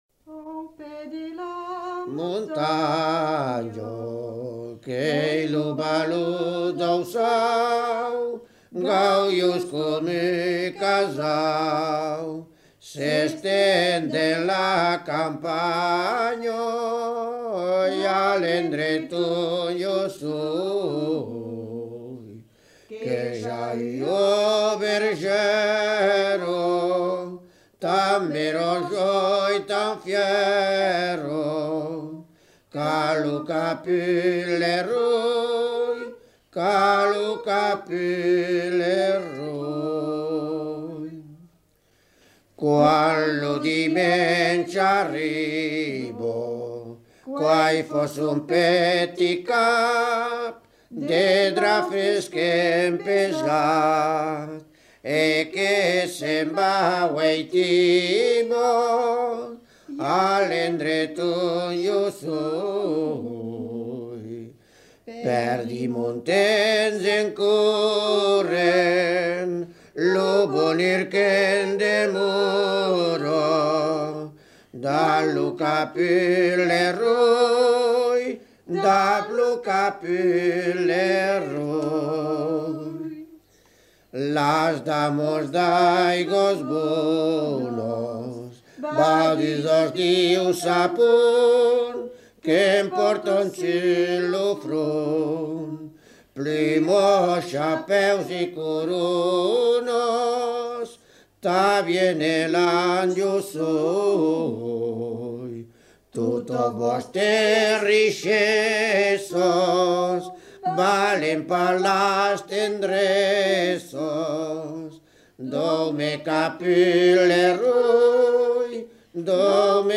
Aire culturelle : Béarn
Lieu : Bielle
Genre : chant
Effectif : 2
Type de voix : voix d'homme ; voix de femme
Production du son : chanté